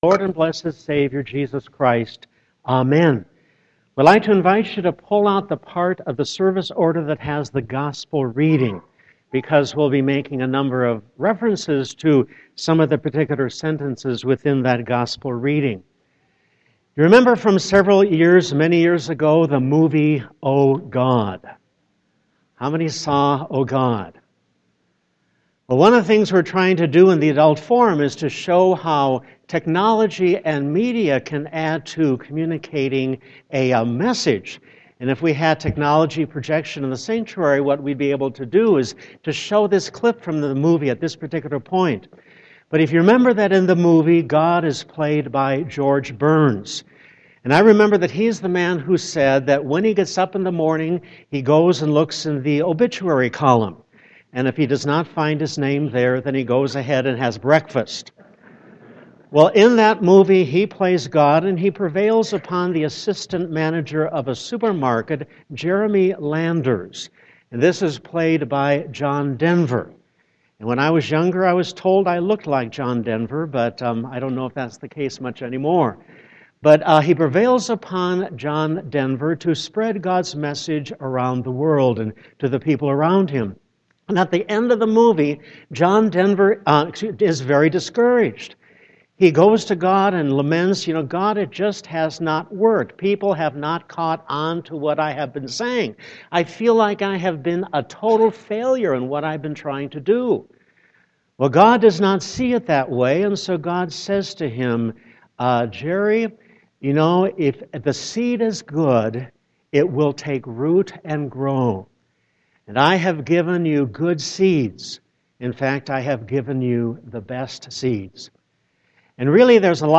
Sermon 6.14.2015